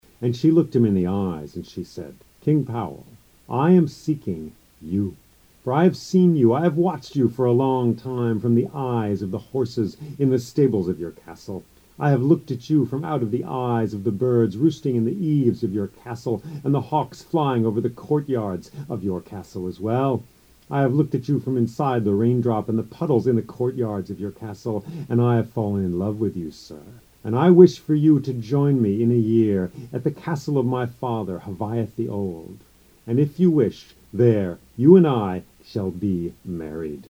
34 Years of Tuesday Night Storytelling